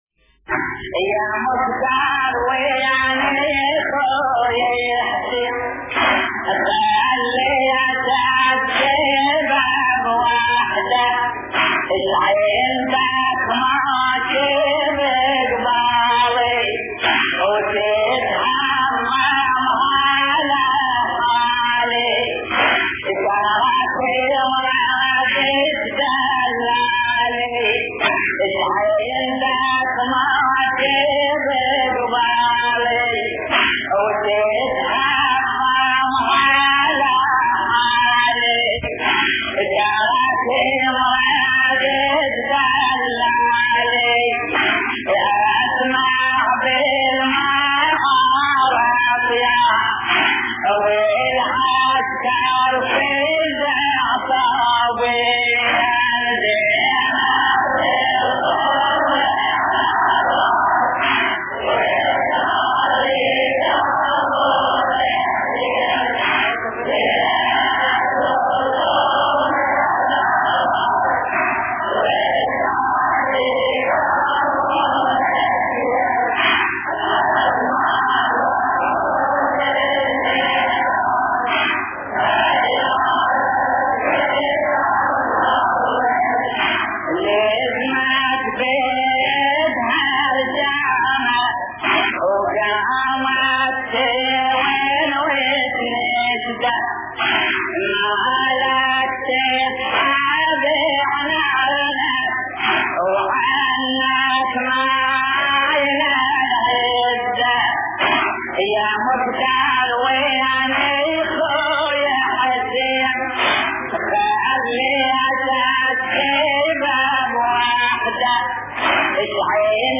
مراثي زينب الكبرى (س)